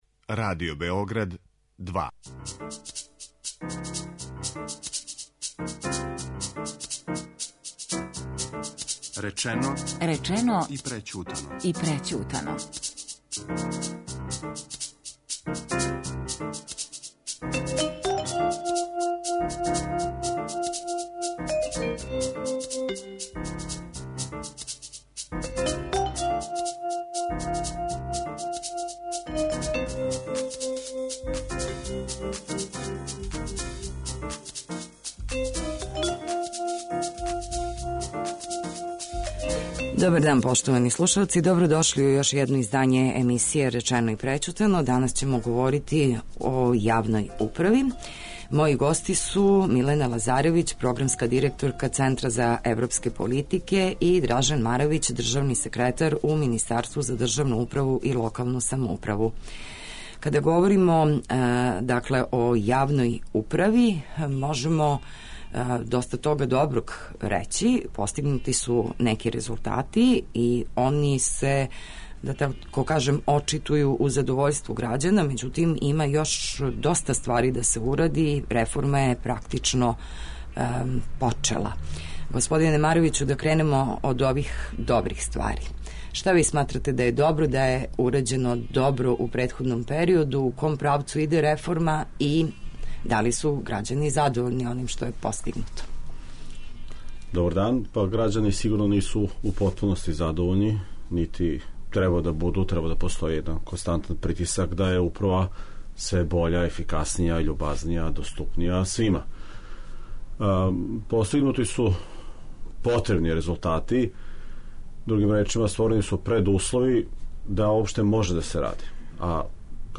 Шта нас чека, којим путем иде реформа, шта ће донети Закон о управном поступку, како се решити вишка запослених, како навести најспособније да конкуришу на важне позиције, како наградити вредне а казнити нераднике - само су нека од питања којима ћемо се бавити у емисији Речено и прећутано. Гости у студију